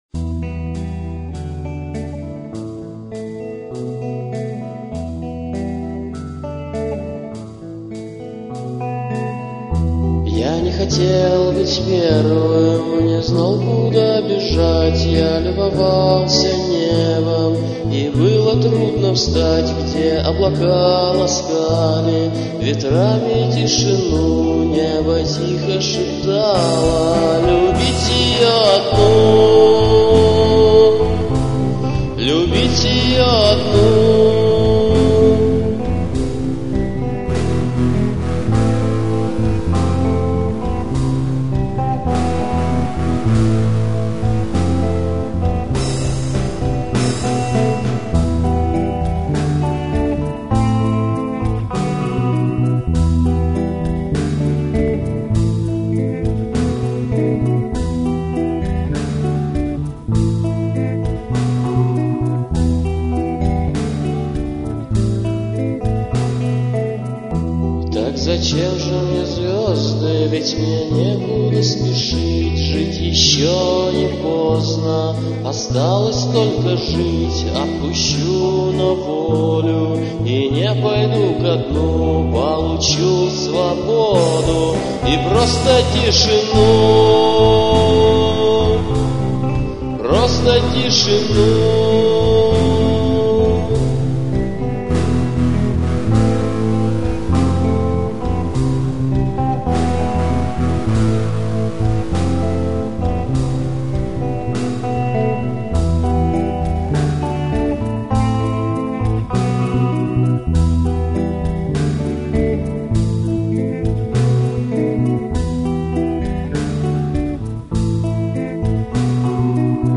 комп-версия